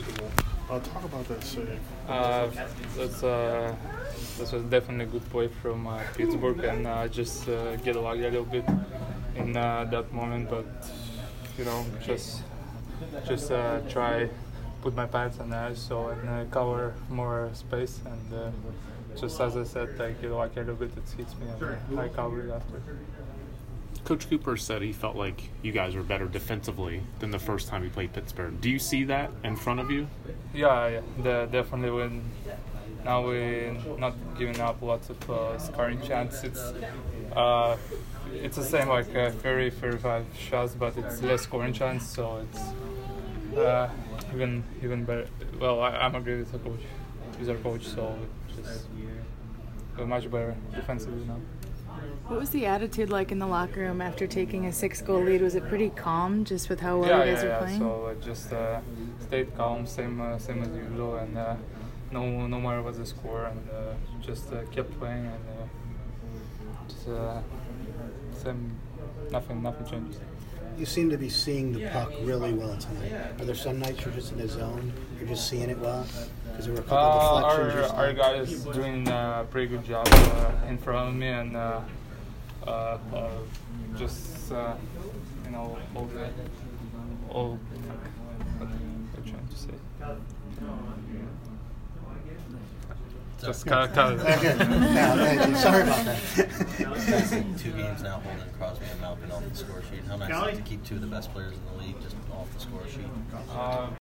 Andrei Vasilevskiy Post-Game 10/21